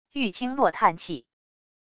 玉清落叹气wav音频